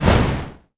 wagic/projects/mtg/bin/Res/sound/sfx/creature.wav
-reduced SFX Quality.